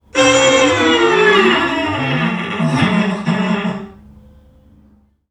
NPC_Creatures_Vocalisations_Robothead [48].wav